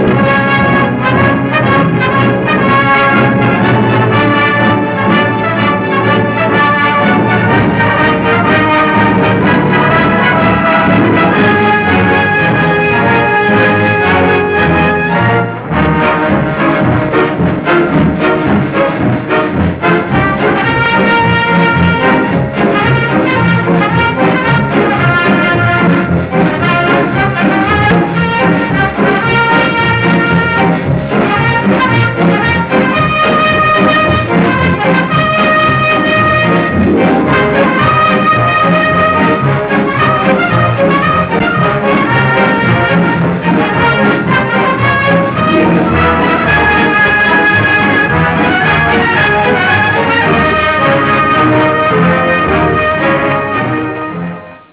Original track music:
ricavata dai nastri del film